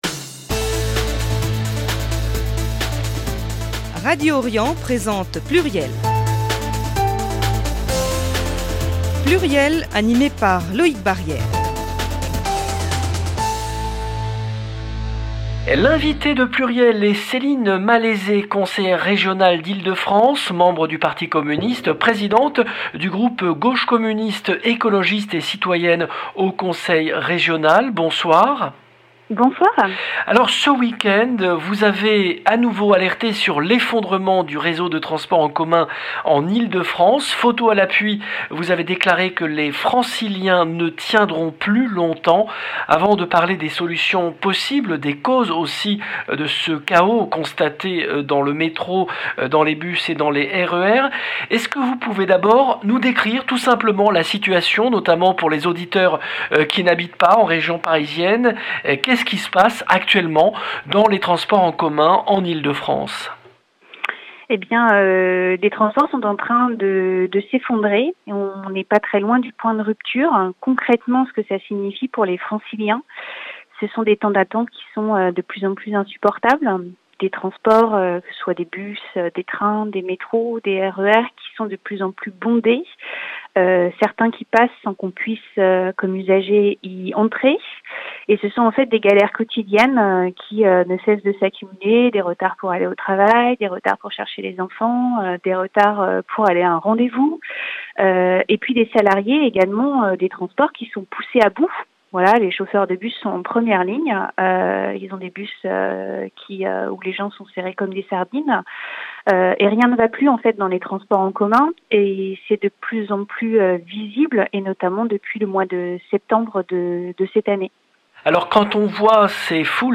Céline Malaisé, conseillère régionale PCF, présidente de groupe.